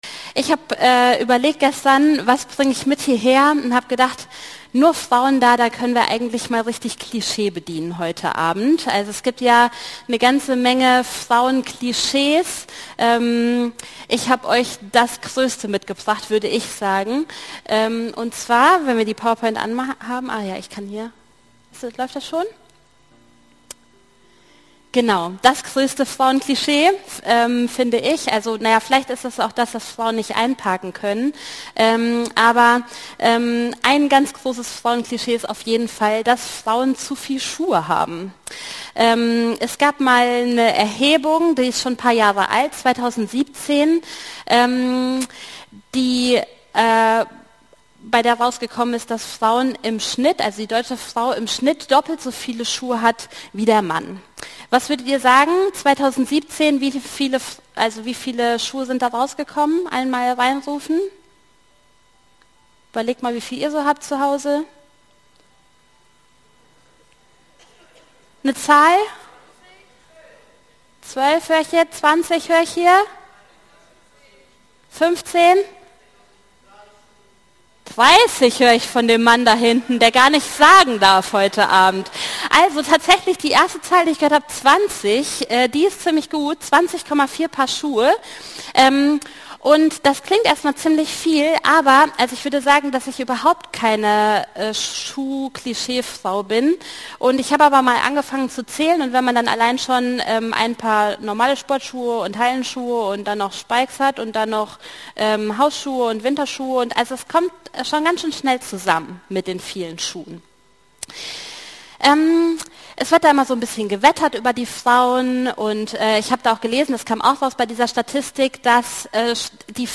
Predigtserie: Gottesdienst